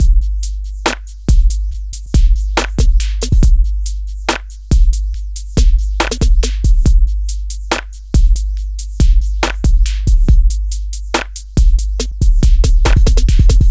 Exodus - Beat 02.wav